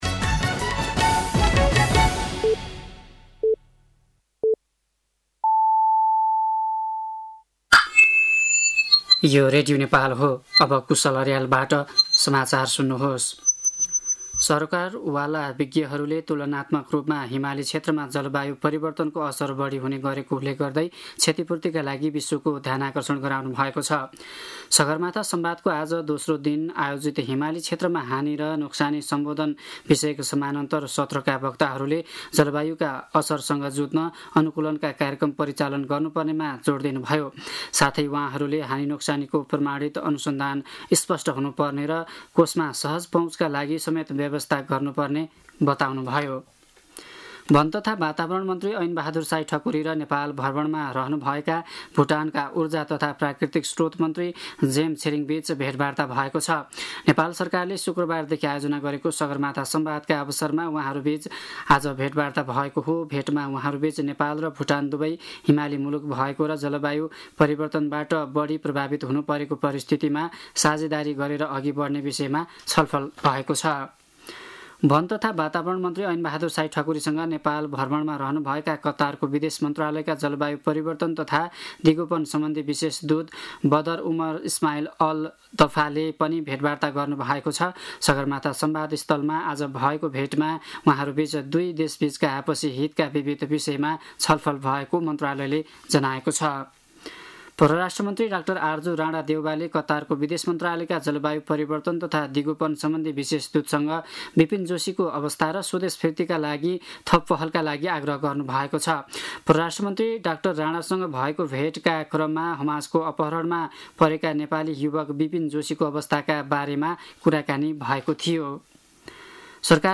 साँझ ५ बजेको नेपाली समाचार : ३ जेठ , २०८२
5.-pm-nepali-news-3.mp3